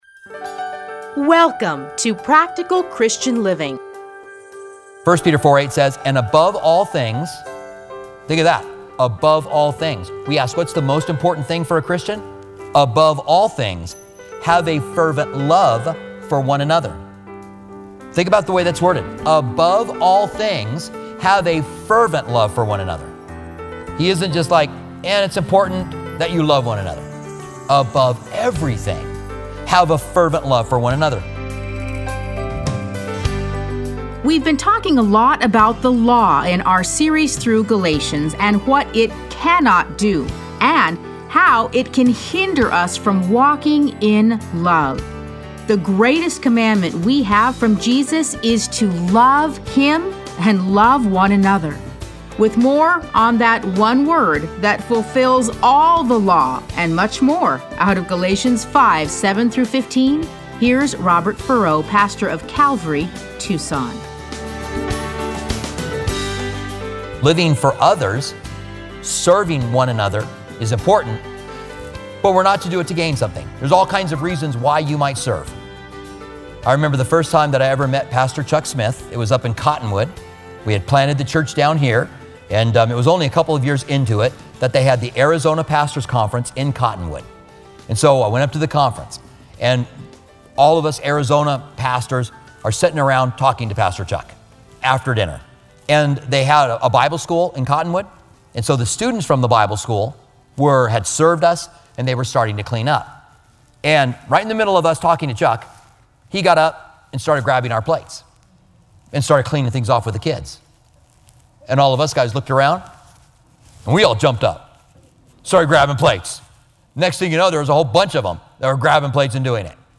Listen to a teaching from Galatians 5:7-15.